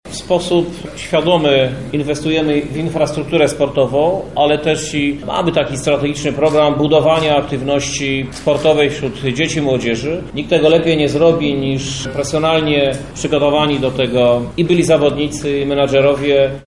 – Stawiamy na sport młodzieżowy – podkreśla prezydent miasta Krzysztof Żuk: